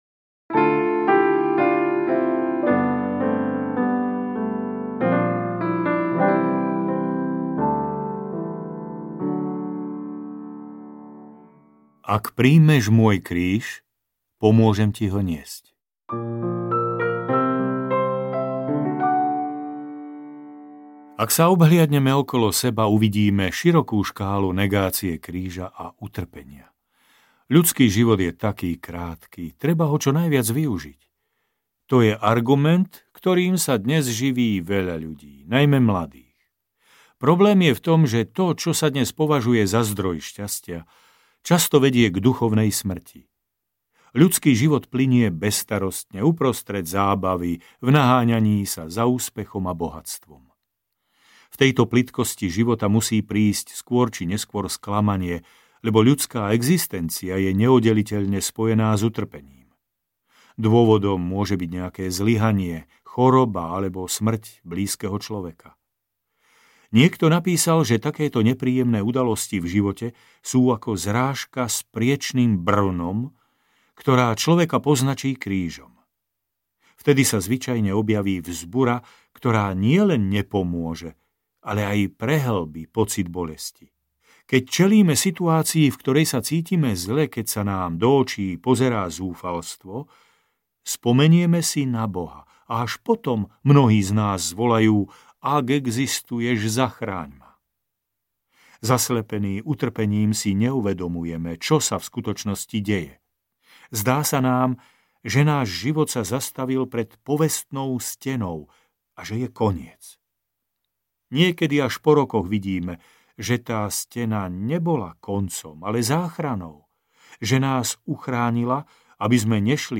40 dní duchovného boja audiokniha
Ukázka z knihy